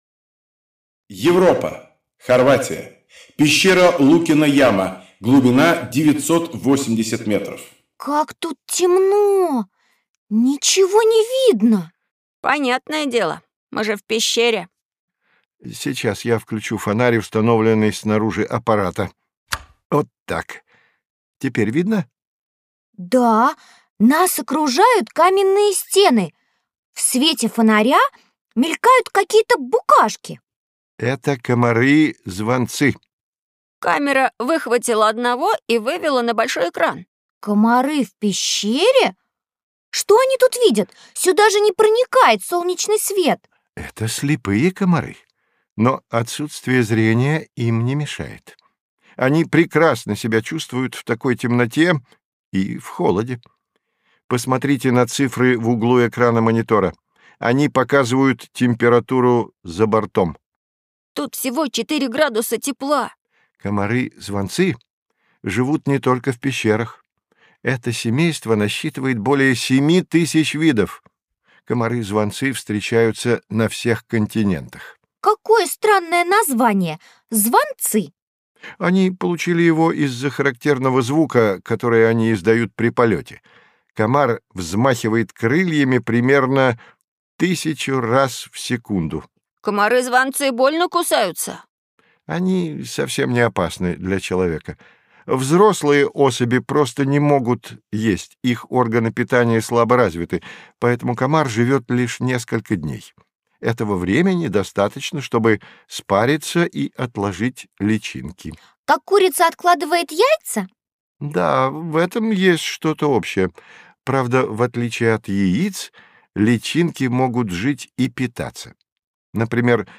Аудиокнига Развивающая аудиоэнциклопедия. Наша планета: Жуки, бабочки и другие насекомые | Библиотека аудиокниг